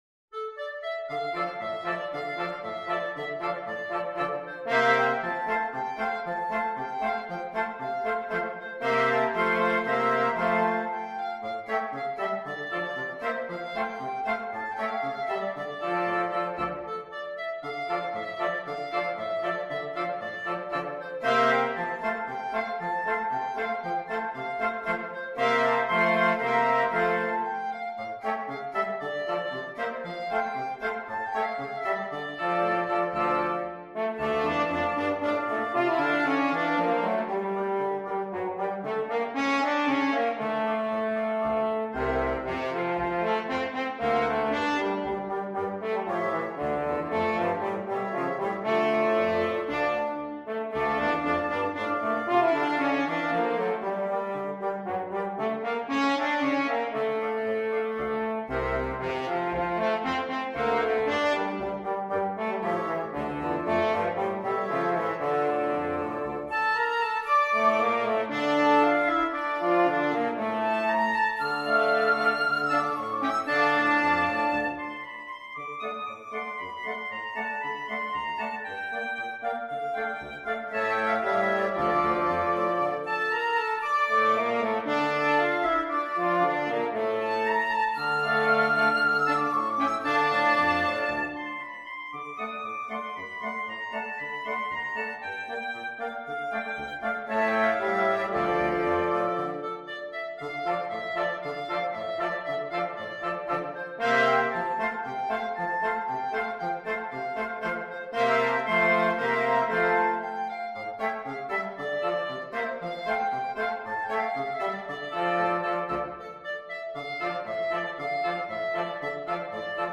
FluteOboeClarinetFrench HornBassoon
Traditional Music of unknown author.
2/4 (View more 2/4 Music)
Allegro =c.116 (View more music marked Allegro)